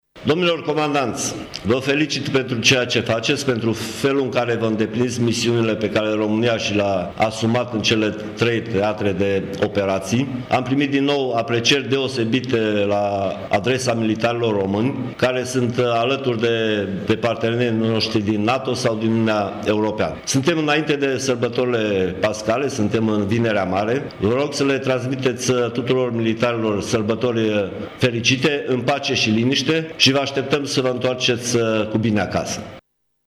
Dușa și-a exprimat urările cu prilejul videoteleconferinţei susținute la sediul Brigăzii 6 Operații speciale „Mihai Viteazul” de la Tîrgu-Mureș.
Ministrul Apărării Naţionale, Mircea Duşa: